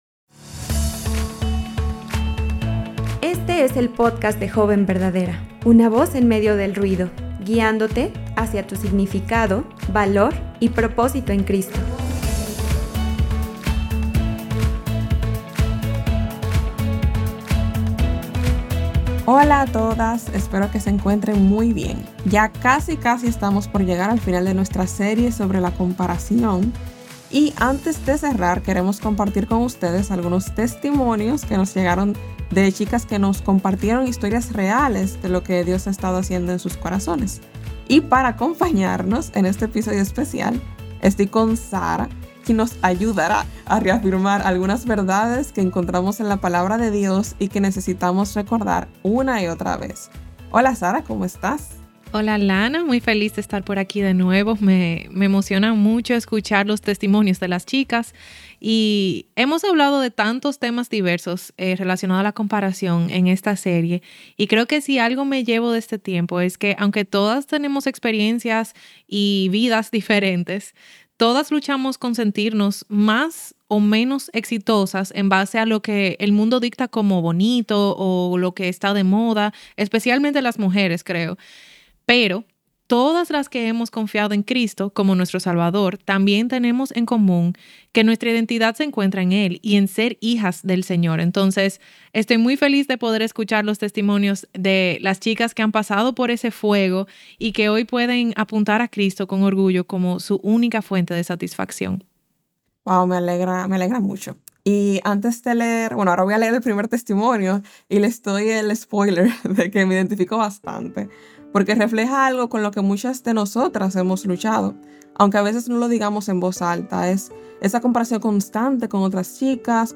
Hoy compartimos testimonios reales de jóvenes que encontraron libertad en Cristo.